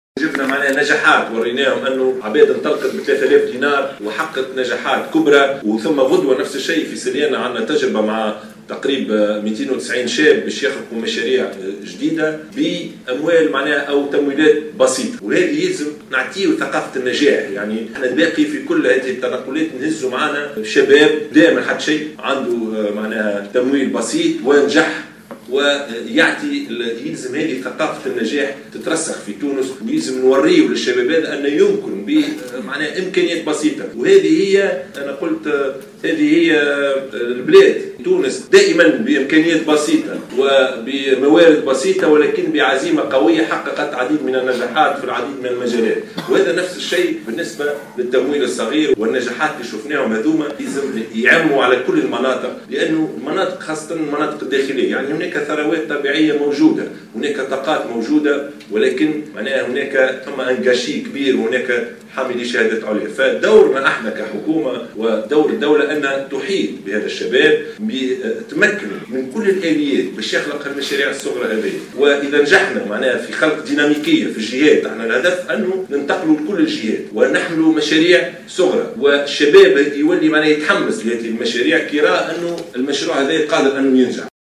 أكد رئيس الحكومة يوسف الشاهد اليوم السبت 24 ديسمبر 2016 على هامش اشرافه على الندوة الوطنية لجمعيات القروض الصغرى " إعادة هيكلة القروض الصغرى من اجل منظومة محترفة و إدماجية مستديمة أنه سينتقل غدا إلى ولاية سليانة لتمكين 290 شابا هناك من بعث مشاريعهم الخاصة بمنحهم تمويلات .